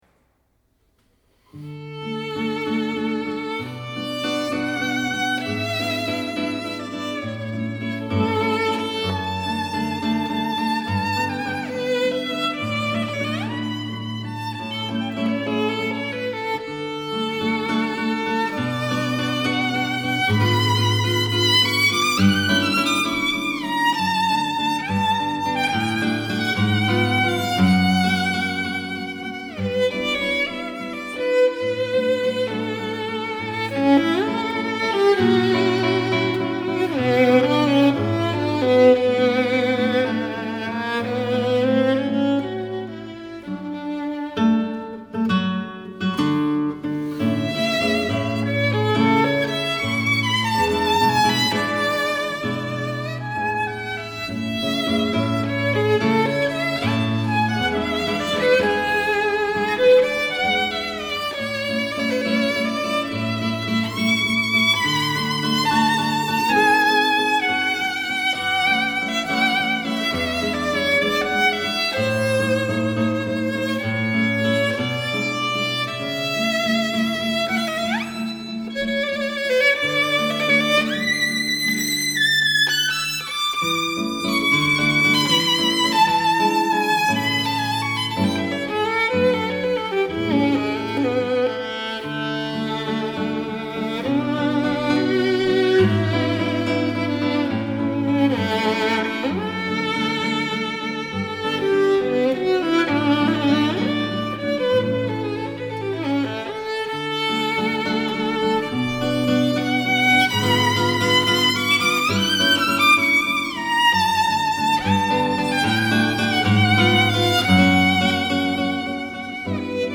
02-Paganini_-Cantabile-In-D-Op.-17.mp3